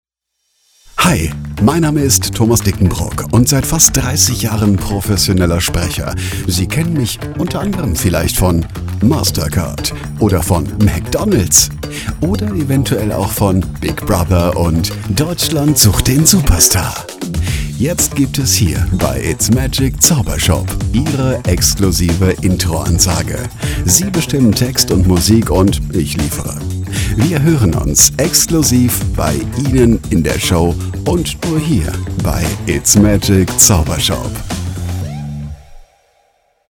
Eine markante männliche Stimme, perfekt produziert, sauber gemischt und genau auf Ihren Stil abgestimmt.
Die Stimme ist klar, warm und präsent. Die Musik sitzt exakt im Timing.
Emotion: Stimme und Musik schaffen sofort Atmosphäre